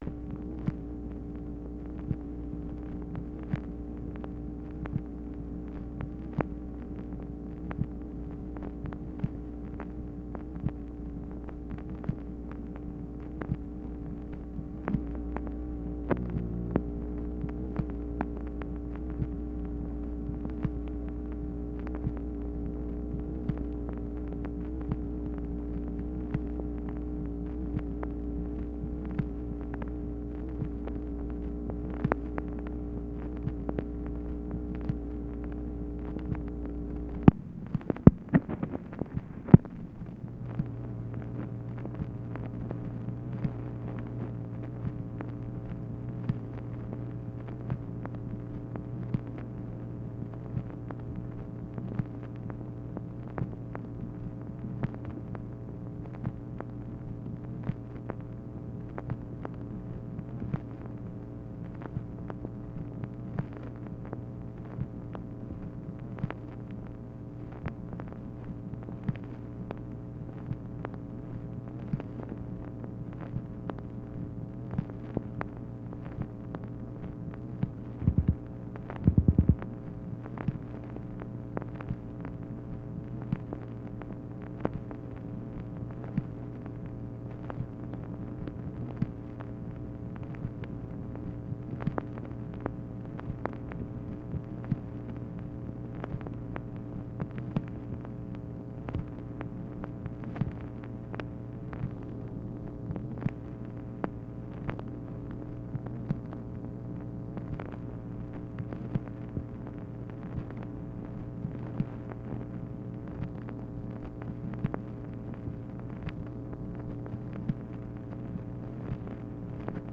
MACHINE NOISE, February 7, 1964